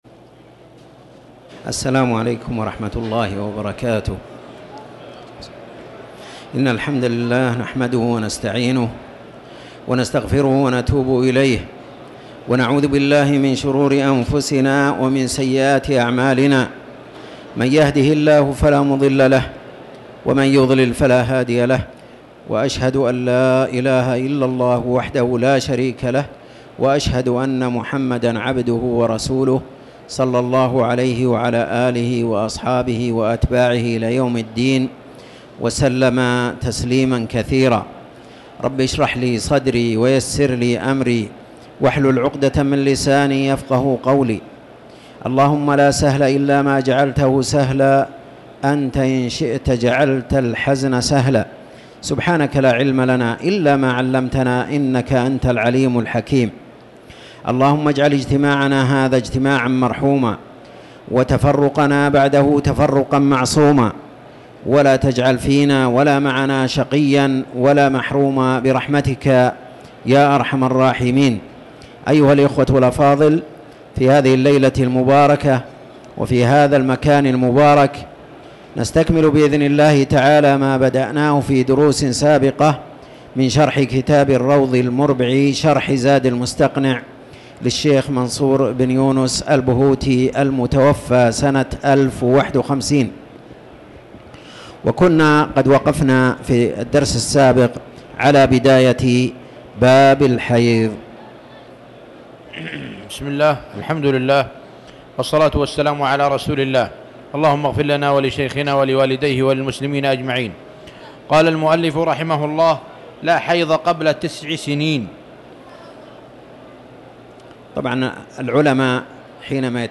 تاريخ النشر ١٨ رجب ١٤٤٠ هـ المكان: المسجد الحرام الشيخ